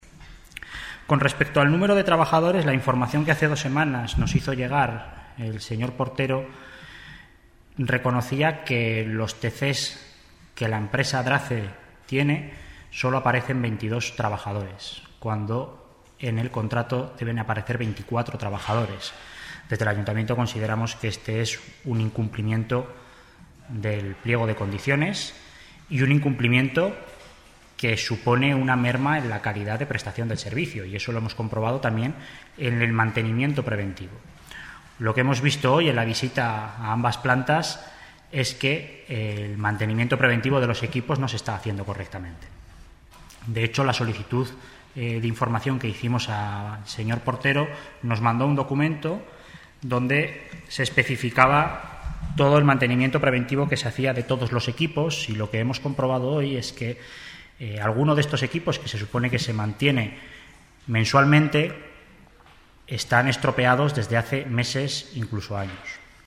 El Consejero de Servicios Públicos y Personal, Alberto Cubero, y la Concejal  Delegada de Medio Ambiente y Movilidad, Teresa Artigas, han explicado en rueda de Prensa que dichas irregularidades ya habían sido denunciadas en el seno del Observatorio de la Contratación en la legislatura pasada.